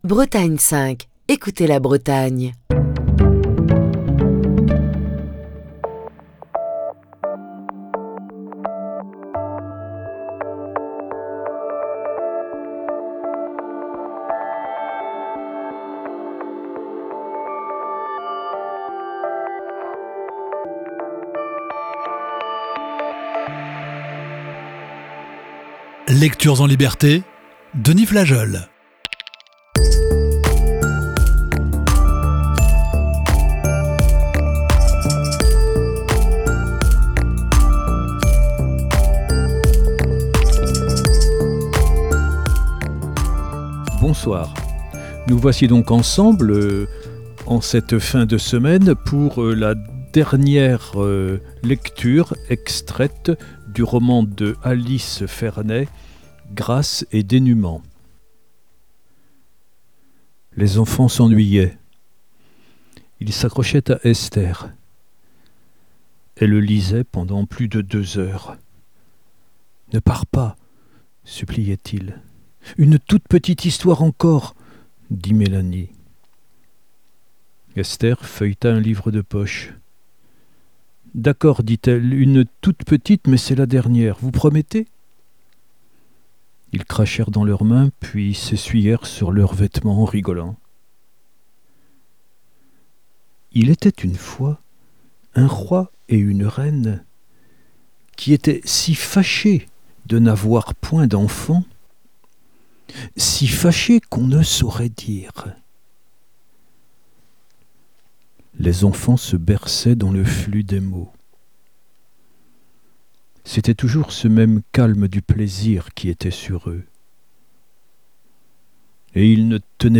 Émission du 17 mai 2024.